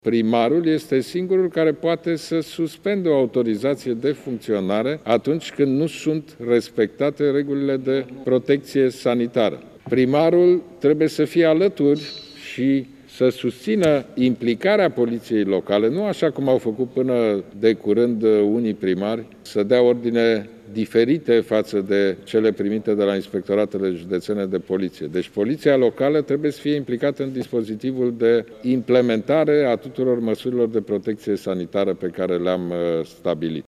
Premierul s-a adresat și primarilor, aleși acum o lună și jumătate.